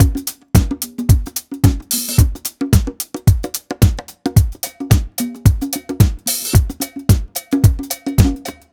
Unison Funk - 10 - 110bpm.wav